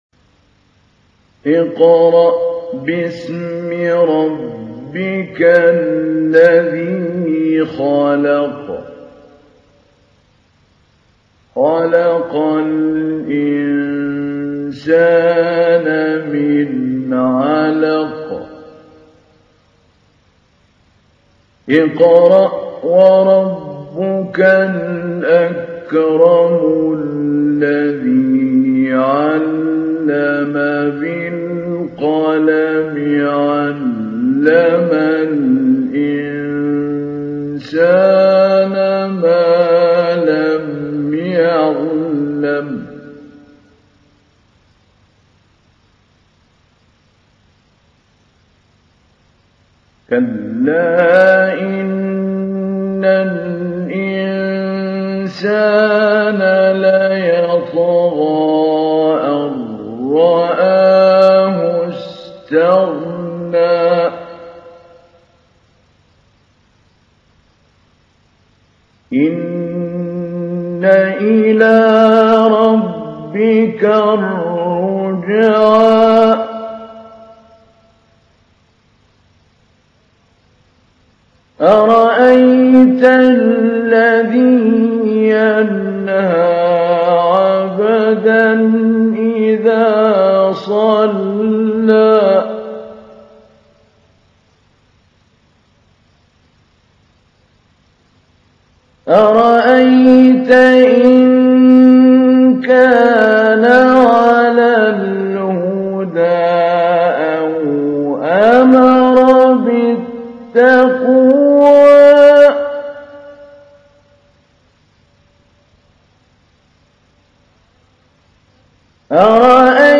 تحميل : 96. سورة العلق / القارئ محمود علي البنا / القرآن الكريم / موقع يا حسين